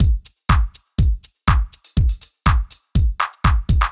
ORG Beat - Mix 1.wav